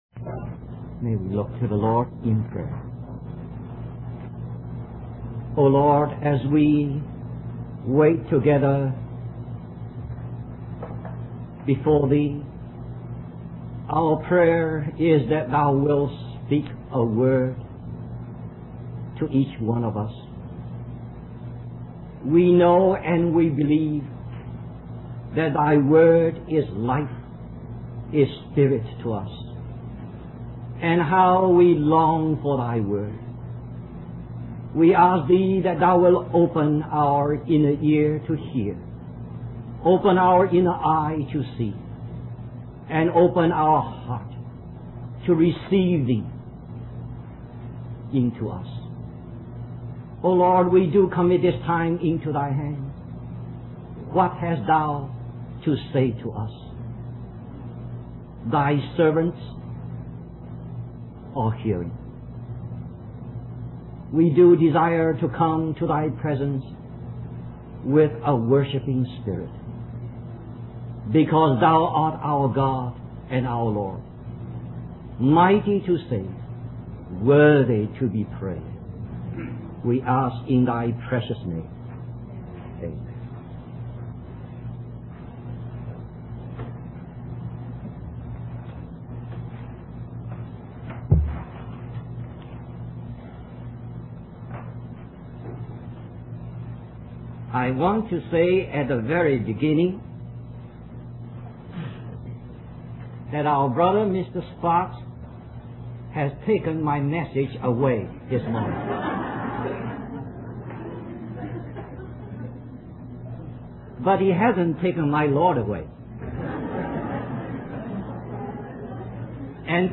Wabanna (Atlantic States Christian Convocation)